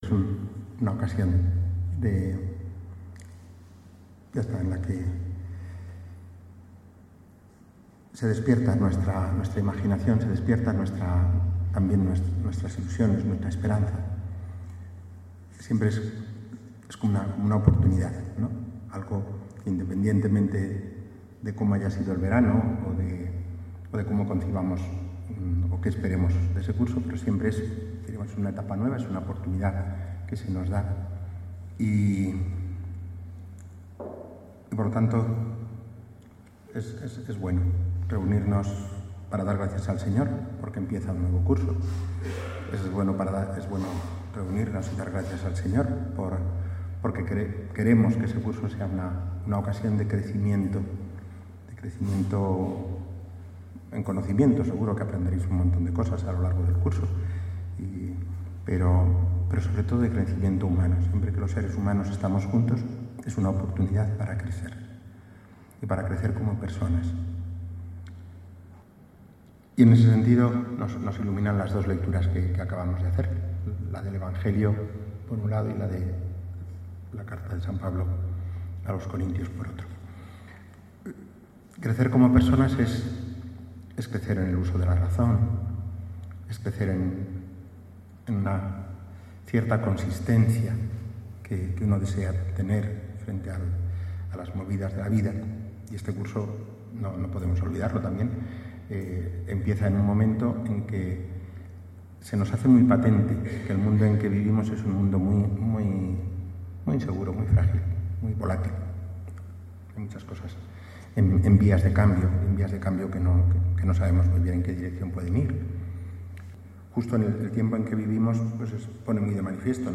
4_oct_2017._Homilia_inauguracion_curso_CES_La_Inmaculada.mp3